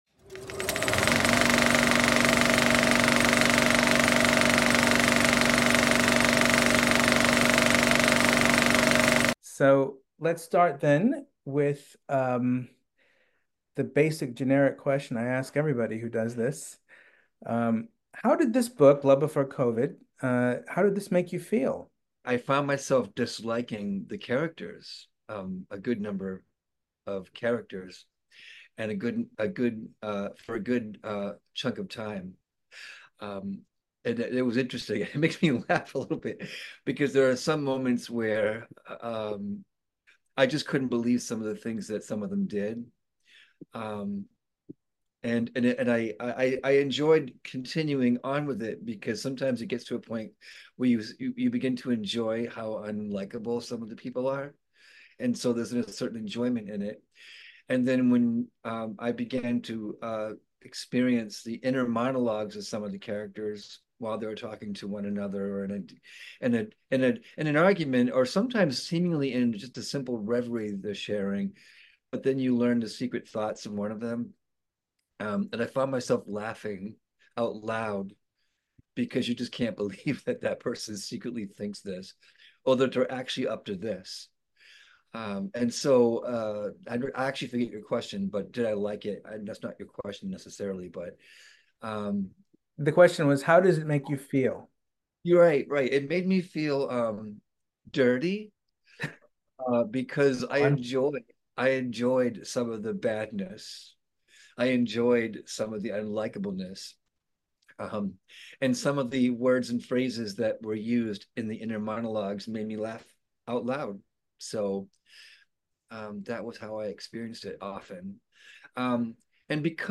4: BOOK TALKS.